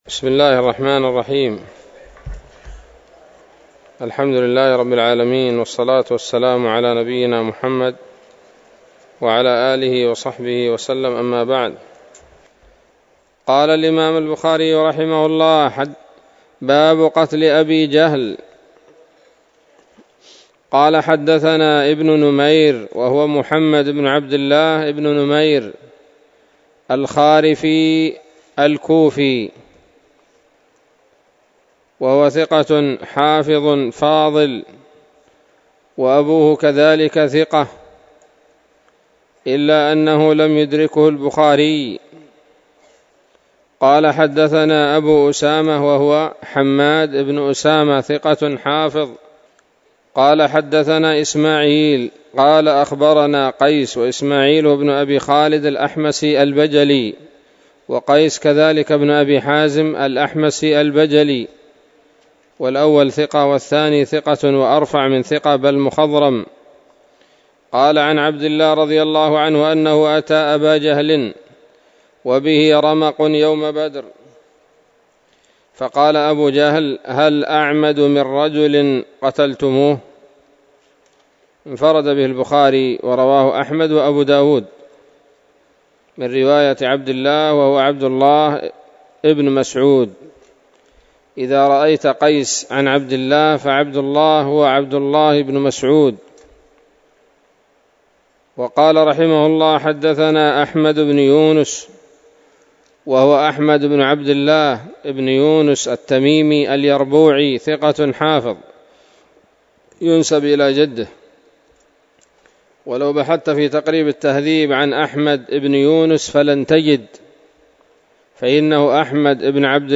الدرس الثامن من كتاب المغازي من صحيح الإمام البخاري